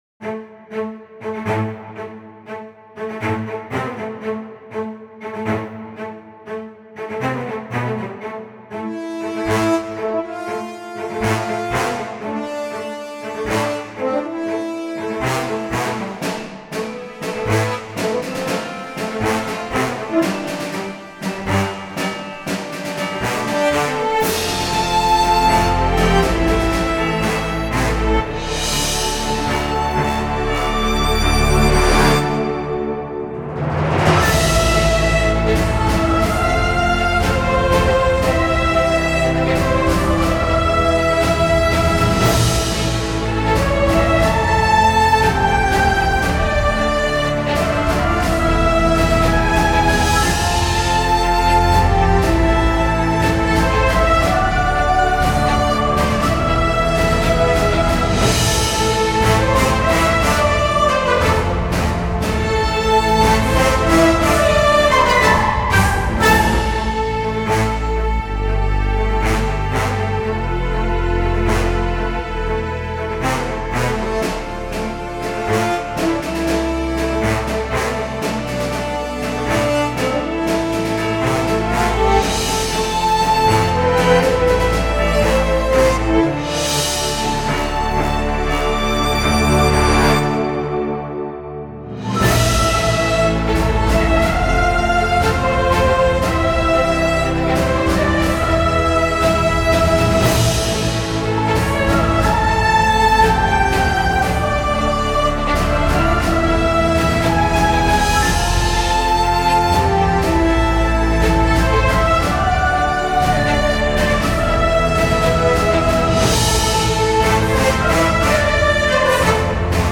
Instrumente - Piano, Strings Tempo - Slow BPM - 56